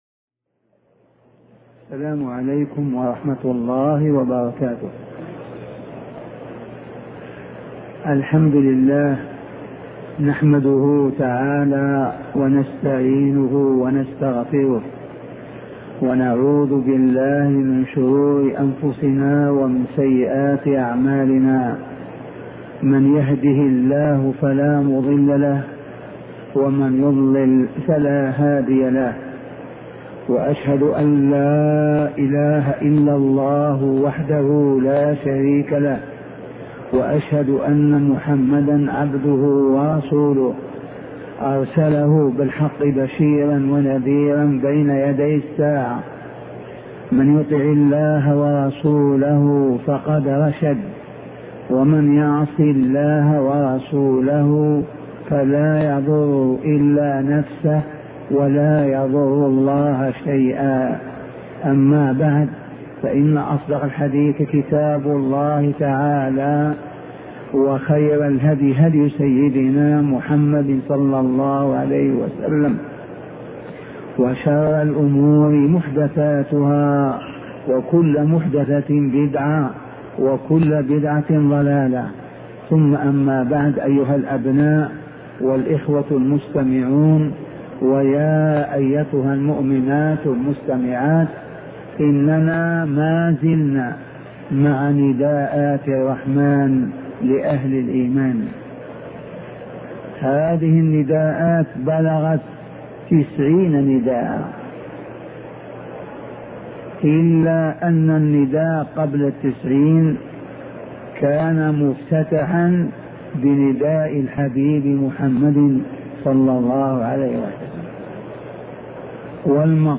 شبكة المعرفة الإسلامية | الدروس | نداءات الرحمن لأهل الإيمان 20 |أبوبكر الجزائري
مدرس بالمسجد النبوي وعضو هيئة التدريس بالجامعة الإسلامية سابقا